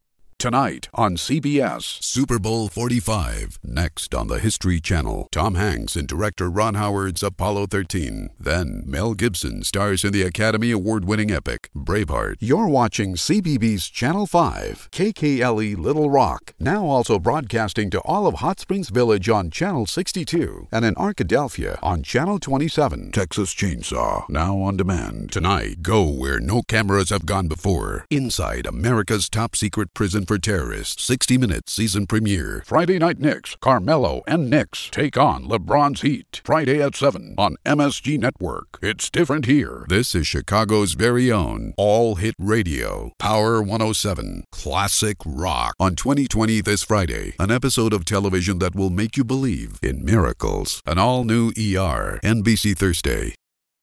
Male
Adult (30-50), Older Sound (50+)
Warm, deep, smooth, authoritative, trailers, edgy, conversational, classic, calming, professional, corporate, natural, smooth, mellow, mature, honest, sincere, trustworthy, serious, soothing, commanding, firm, educational, masculine, husky, dramatic, serious, gruff, epic, gravelly, burly.
Radio / TV Imaging
0601Radio___TV_Imaging.mp3